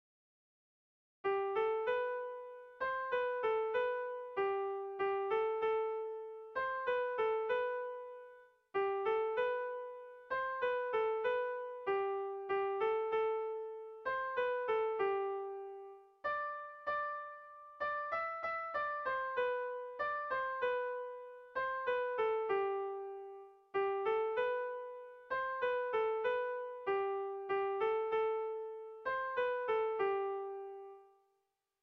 Erlijiozkoa
Zortziko txikia (hg) / Lau puntuko txikia (ip)
AABA